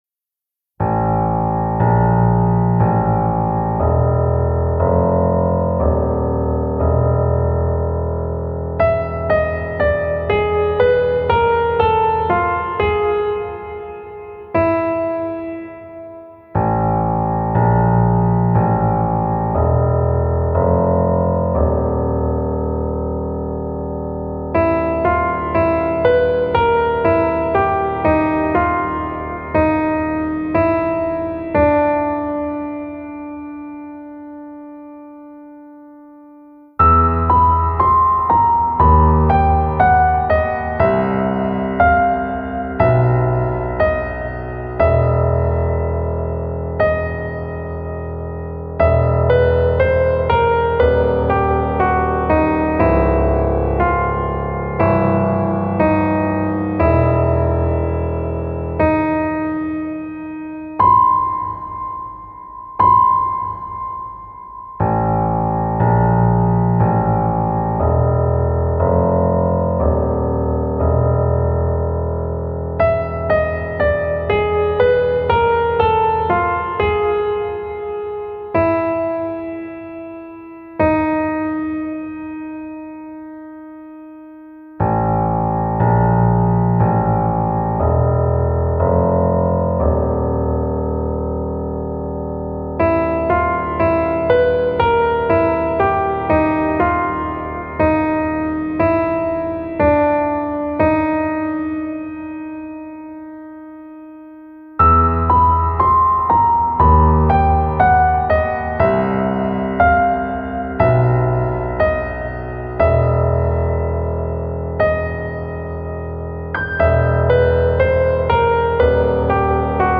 【用途/イメージ】　怪談　都市伝説　不気味　怖い
ピアノ曲